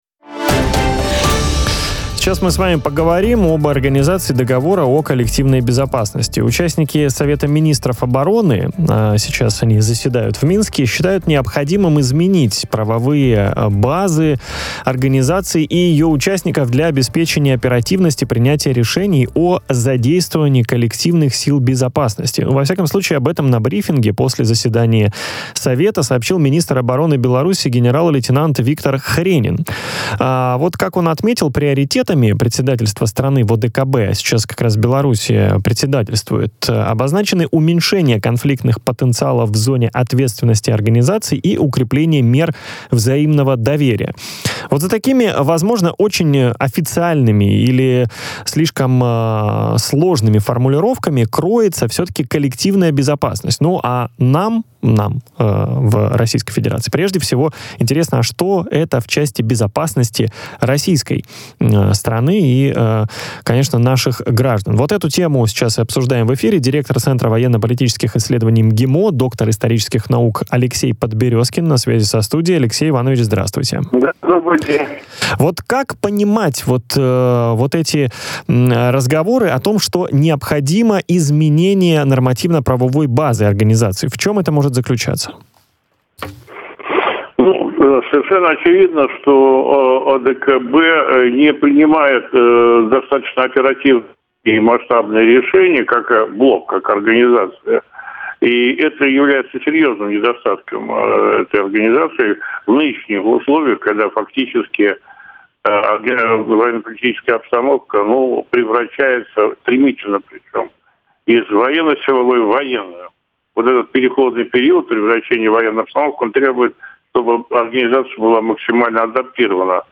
в эфире радио Sputnik.